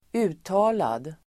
Uttal: [²'u:ta:lad]